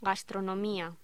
Locución: Gastronomía